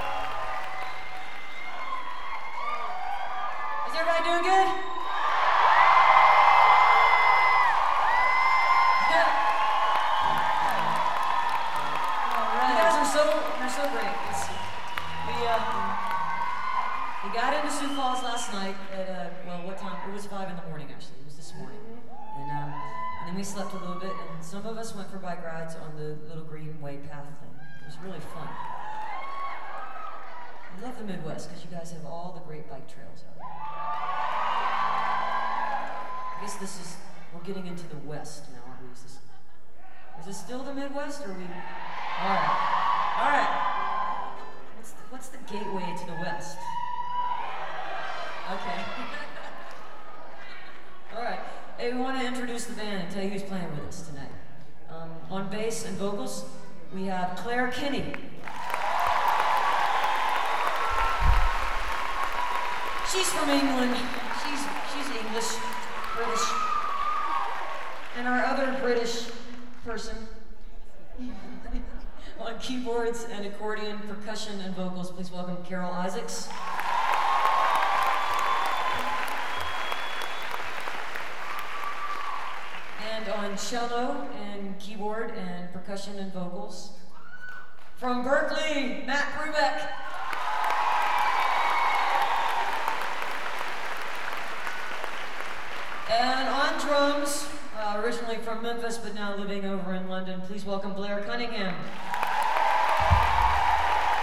lifeblood: bootlegs: 2000-06-24: washington pavillion - sioux falls, south dakota
08. talking with the crowd (1:44)